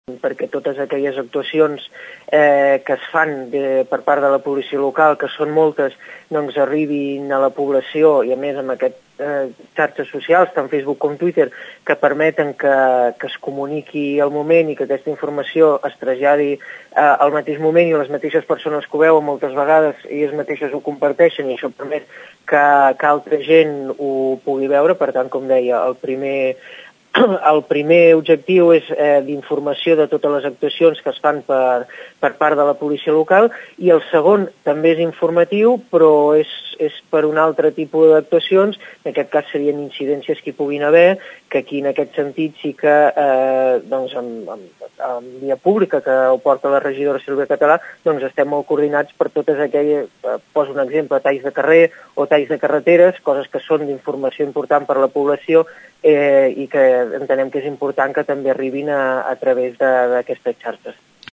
Actualment ja els podeu trobar a twitter i facebook, aquests dos comptes seran gestionats directament des del mateix cos amb la col·laboració estreta del departament de comunicació de l’Ajuntament de Tordera. El regidor responsable de l’àrea de seguretat, Xavier Martin explica els objectius dels nous perfils socials.